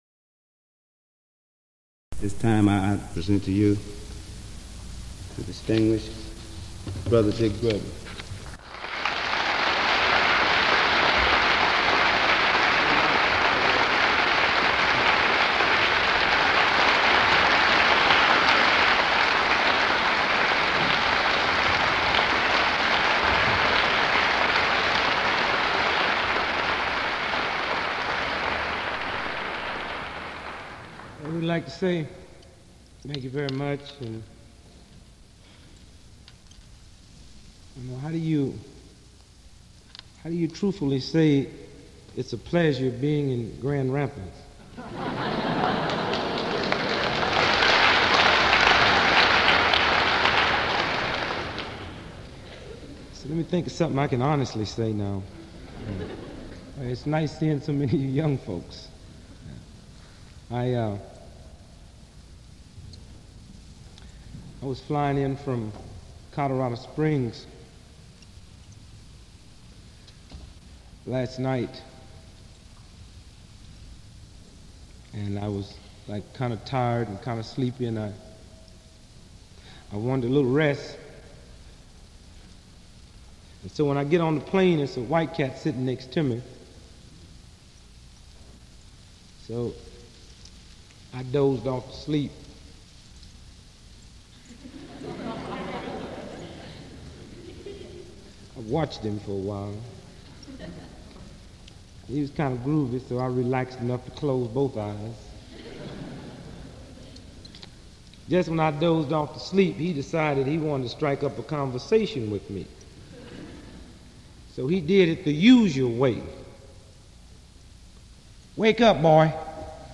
Civil Rights activist and entertainer Dick Gregory spoke at Fountain Street Church in 1969
Today, I wanted to share an audio recording of the Comedian and Civil Rights activist Dick Gregory, who spoke at Fountain Street Church in January of 1969.